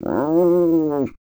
Divergent / mods / Soundscape Overhaul / gamedata / sounds / monsters / cat / 11.ogg